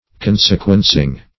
Consequencing \Con"se*quen`cing\, n.